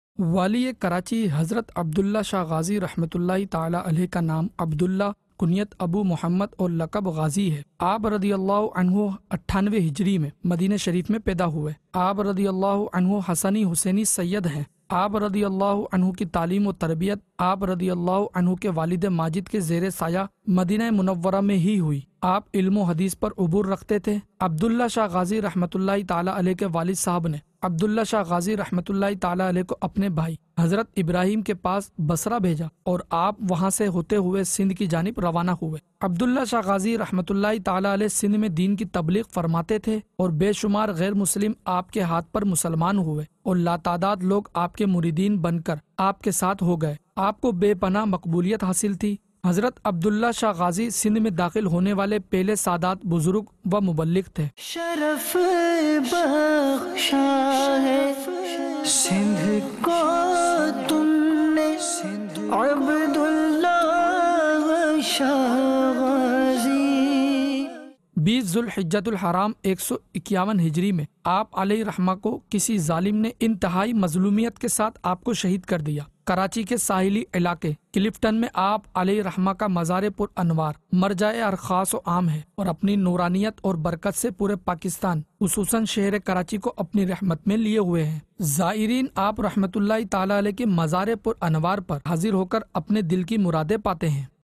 News Clip Urdu - 11 August 2020 - Hazrat Syeduna Abdullah Shah Ghazi رحمتہ اللہ تعا لیٰ علیہ Ki Seerat Par Report Aug 13, 2020 MP3 MP4 MP3 Share حضرت سیدنا عبداللہ شاہ غازی رحمتہ اللہ تعا لیٰ علیہ کی سیرت پررپورٹ